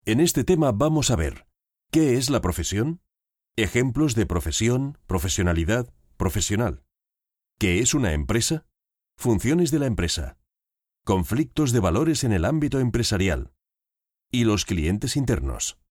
Commercieel, Vriendelijk, Warm, Zacht, Zakelijk
E-learning